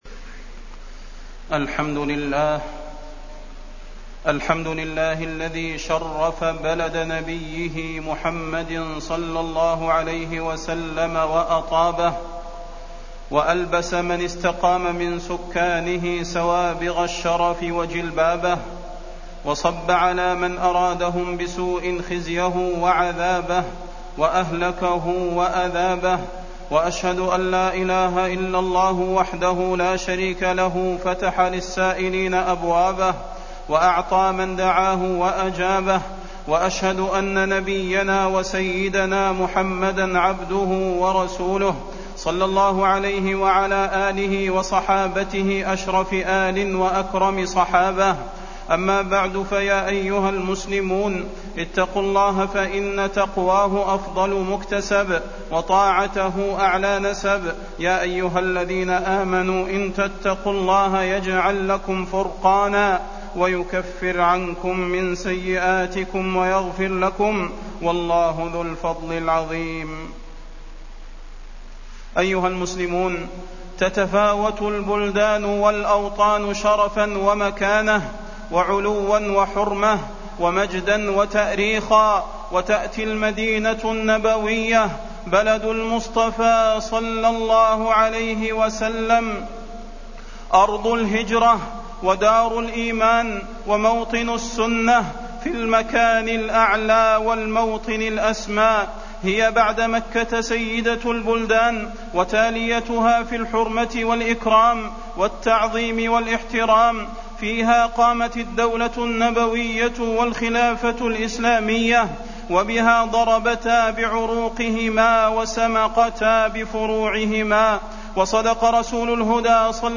تاريخ النشر ٢٧ رجب ١٤٢٨ هـ المكان: المسجد النبوي الشيخ: فضيلة الشيخ د. صلاح بن محمد البدير فضيلة الشيخ د. صلاح بن محمد البدير فضل المدينة